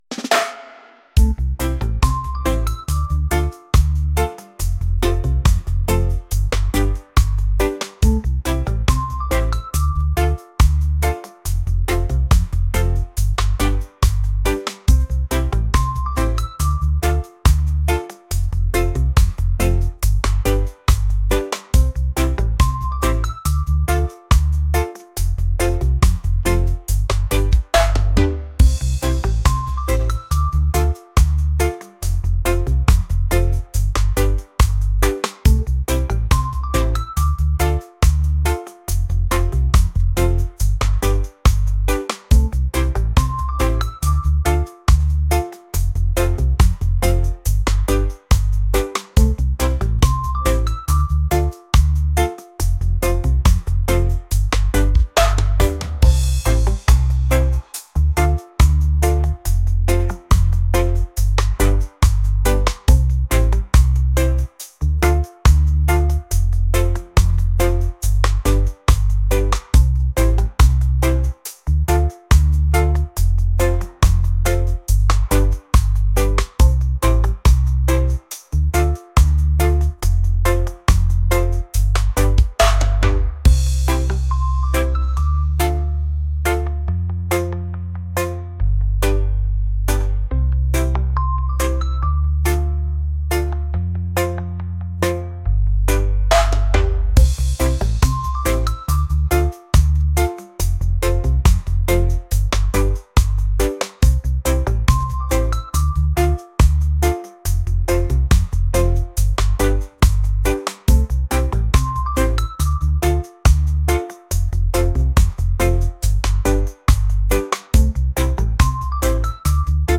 reggae | lofi & chill beats | ambient